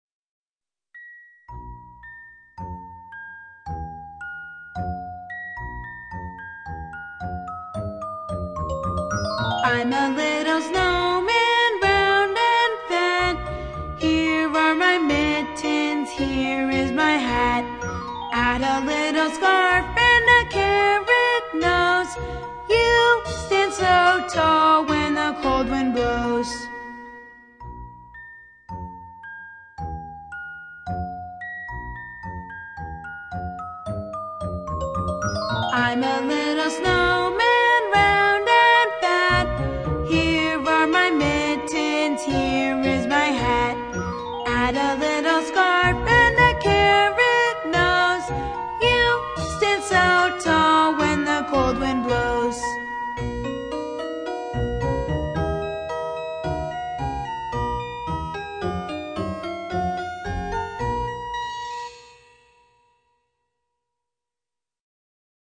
在线英语听力室英语儿歌274首 第125期:Little Snowman的听力文件下载,收录了274首发音地道纯正，音乐节奏活泼动人的英文儿歌，从小培养对英语的爱好，为以后萌娃学习更多的英语知识，打下坚实的基础。